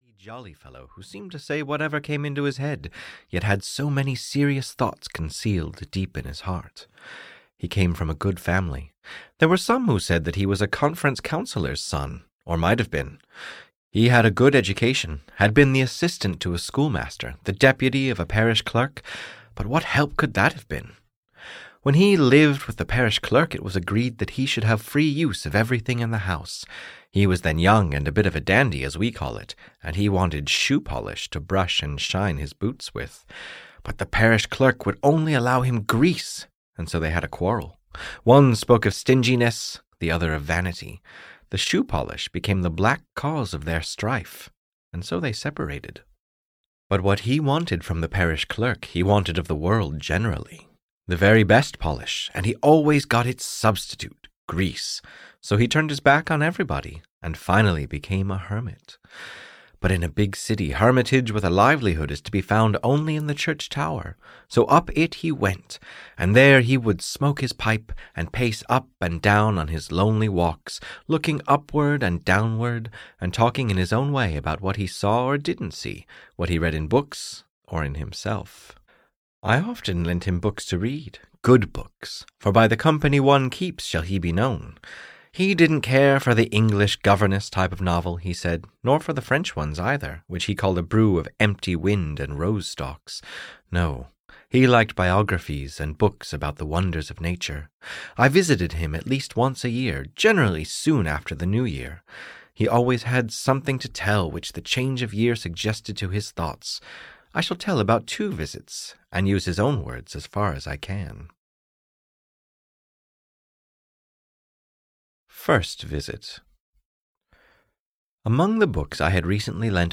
Ole, the Tower-Keeper (EN) audiokniha
Ukázka z knihy